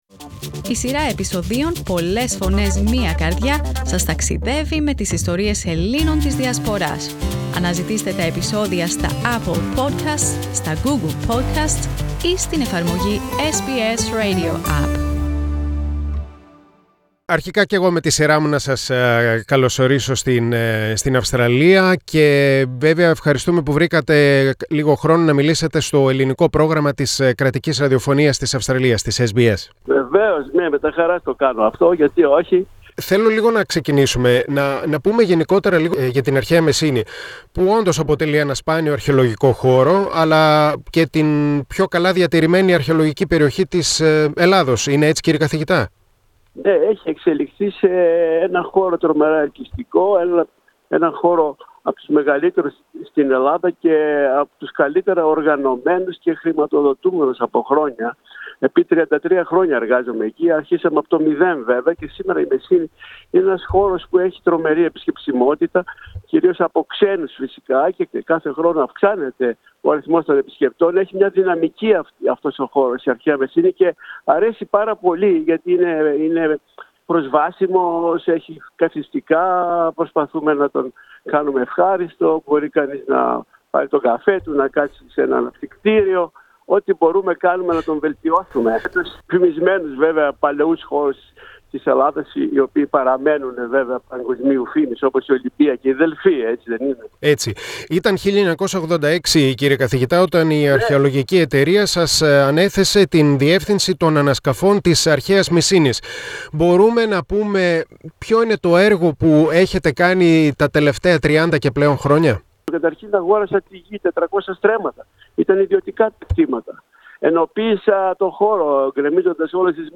Ο καθηγητής Πέτρος Θέμελης, από στους σημαντικότερους Έλληνες αρχαιολόγους, διέπρεψε με τις συστηματικές πολύχρονες ανασκαφές του και αναστηλώσεις που αποκάλυψαν μια ολόκληρη πόλη, την Αρχαία Μεσσήνη. Μίλησε στο Ελληνικό Πρόγραμμα της Ραδιοφωνίας SBS.